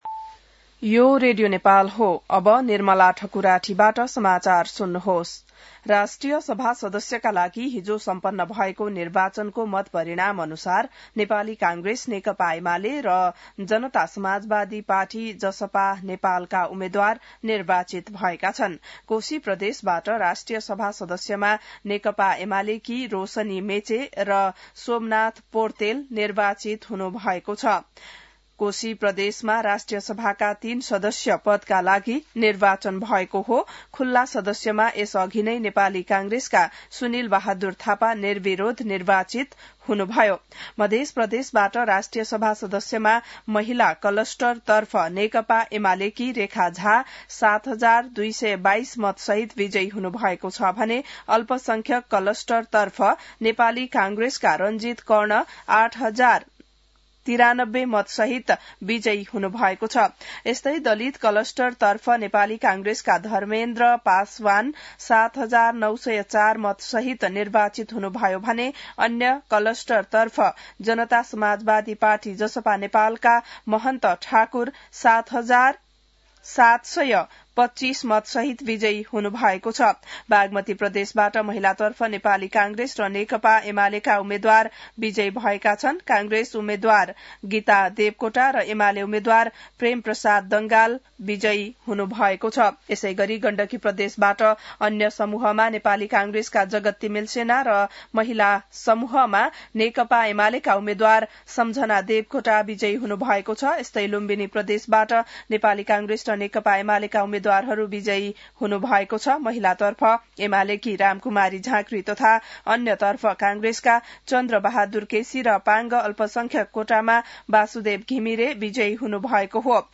बिहान १० बजेको नेपाली समाचार : १२ माघ , २०८२